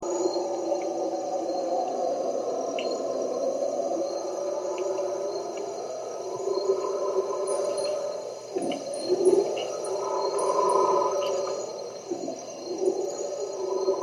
All'alba invece ci svegliano le scimmie urlatrici con i loro cori.
Clicca qui per sentire i cori delle urlatrici
urlatrici.mp3